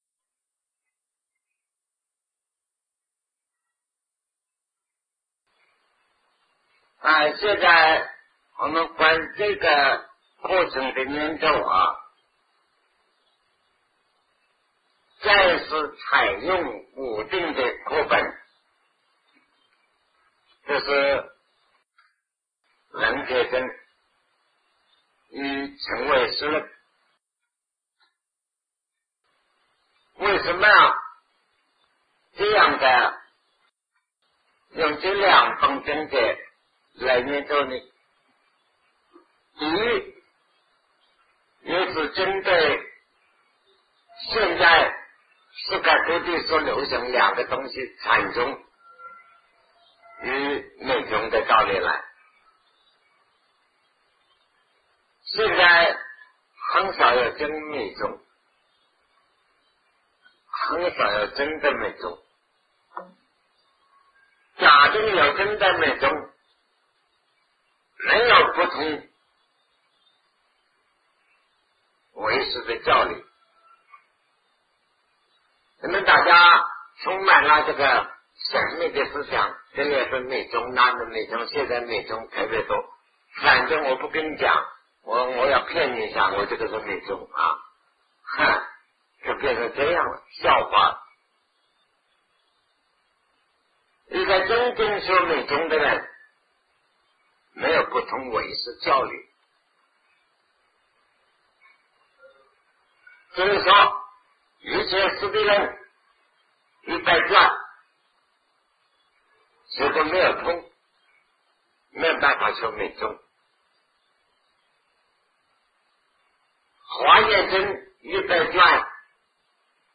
为何要讲唯识 南怀瑾先生讲唯识与中观1980代初于台湾002(上)